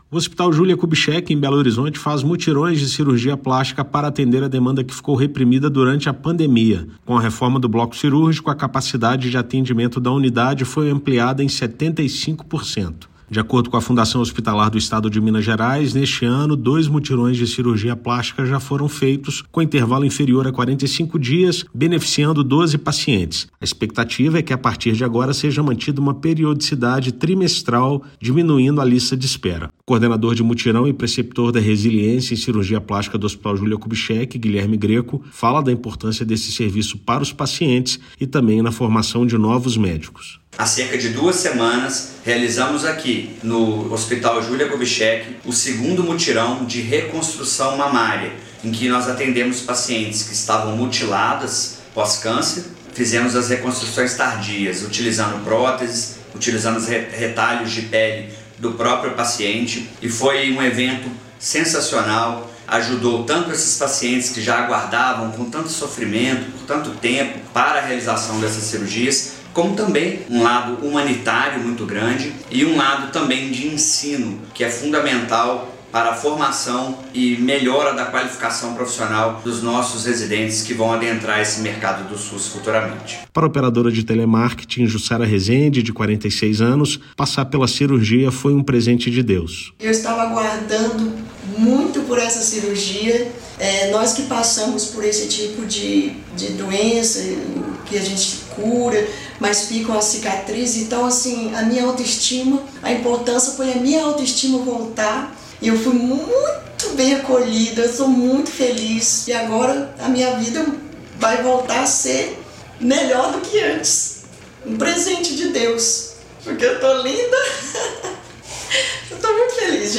[RÁDIO] Mutirões de cirurgia plástica da Fhemig atendem demanda reprimida pela pandemia e resgatam autoestima de pacientes
Com reforma do bloco cirúrgico, serviço do Hospital Júlia Kubitschek ampliou capacidade de atendimento em 75%; saiba como serviço funciona. Ouça matéria de rádio.